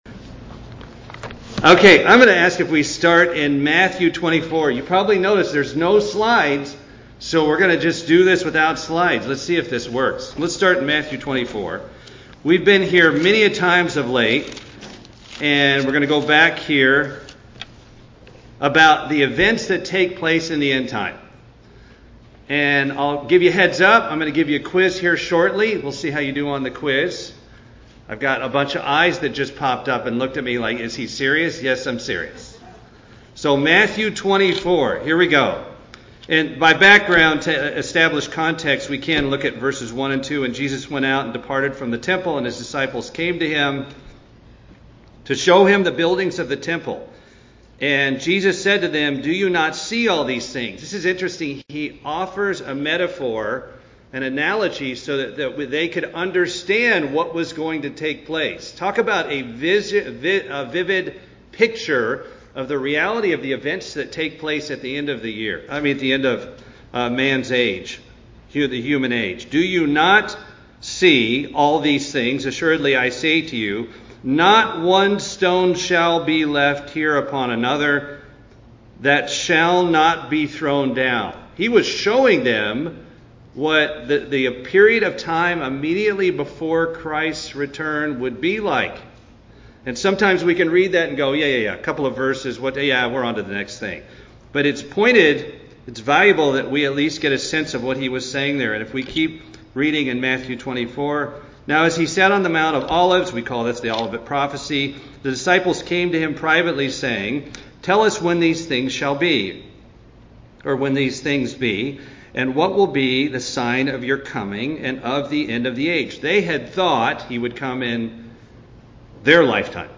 Bible Study Course Lesson 4: Why Does God Allow Suffering?
Given in Atlanta, GA